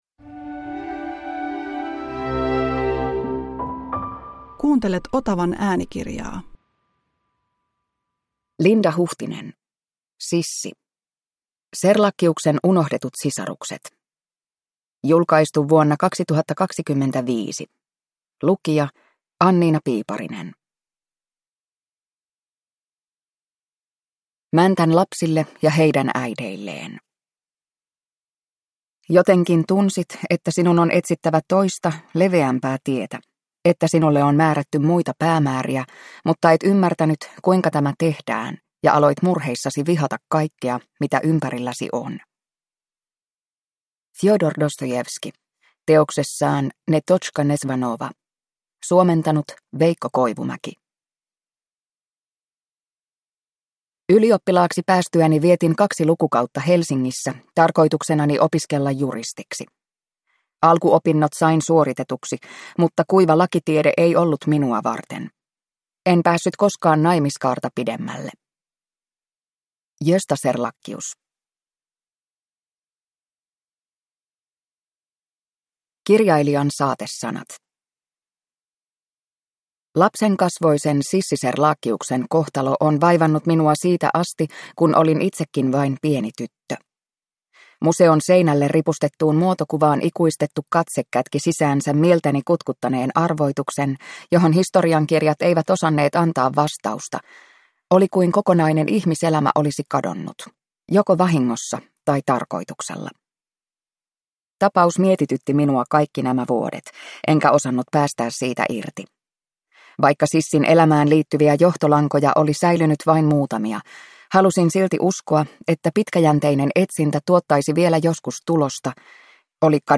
Sissi – Ljudbok